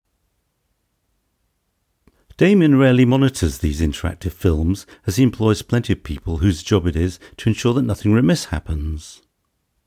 audiobook harshness after using filters
It’s a lot better than your previous microphone.
I got a new microphone … Any comments?